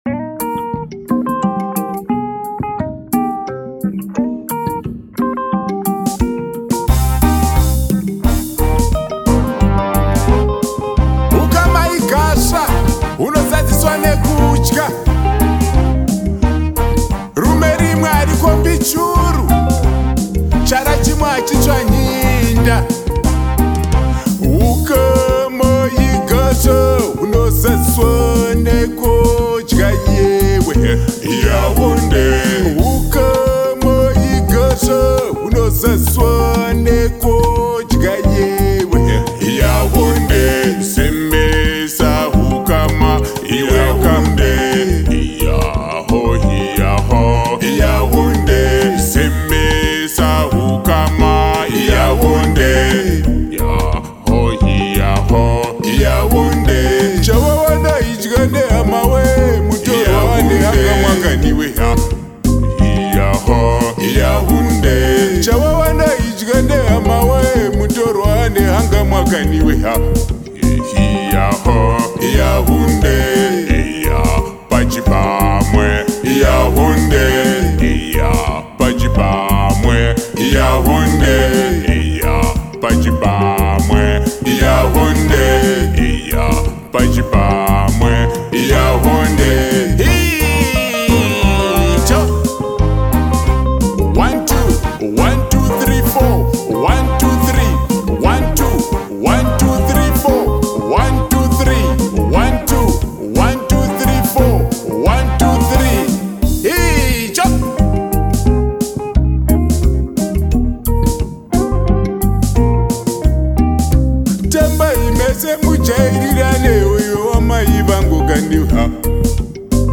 Vi spelar LEVANDE DANS-MUSIK FRÅN ZIMBABWE.
• Folkmusik/världsmusik